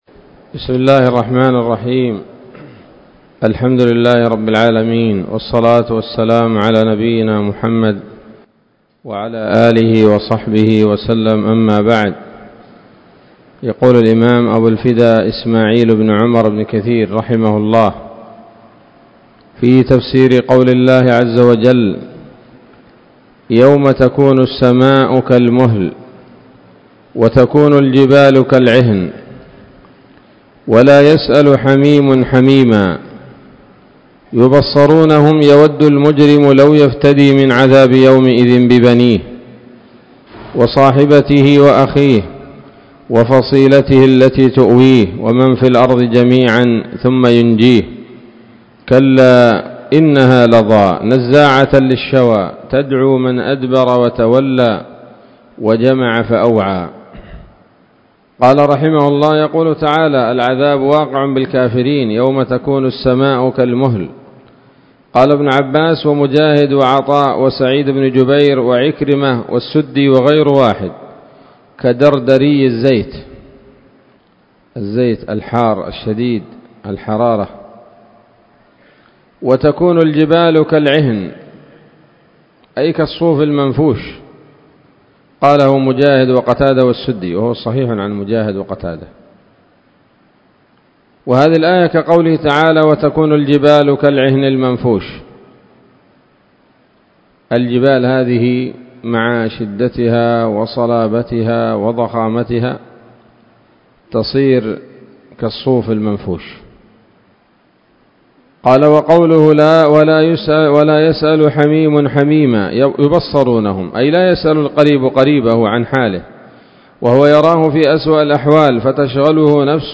الدرس الثالث من سورة المعارج من تفسير ابن كثير رحمه الله تعالى